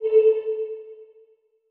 line-clear.wav